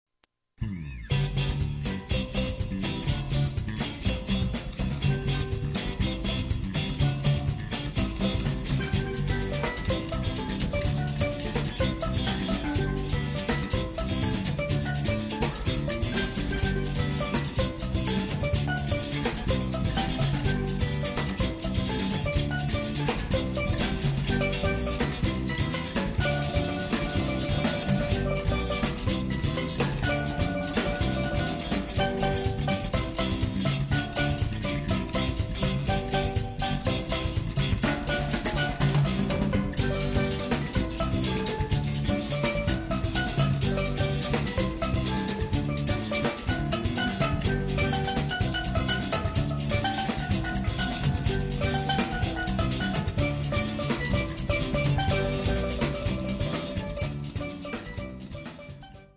Guitars
Bass
Fusing funk, world beat, jazz and joy